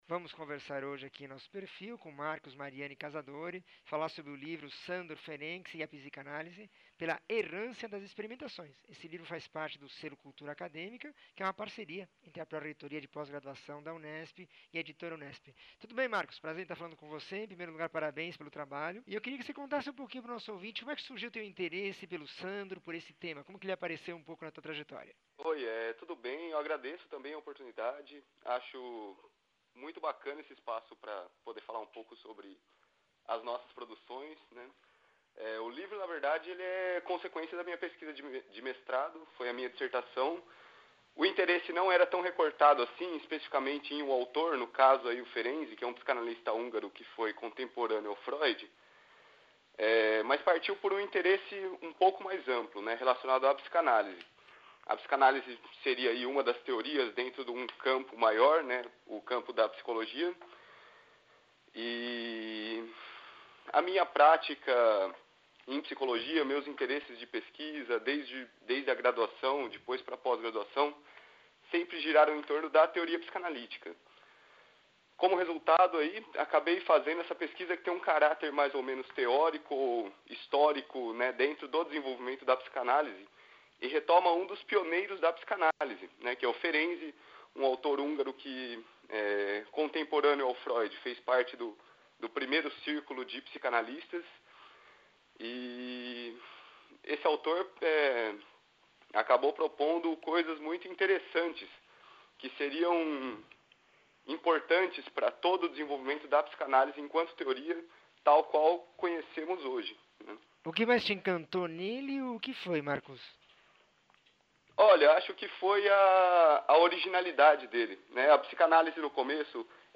entrevista 1769